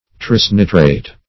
Search Result for " trisnitrate" : The Collaborative International Dictionary of English v.0.48: Trisnitrate \Tris*ni"trate\, n. [Gr.
trisnitrate.mp3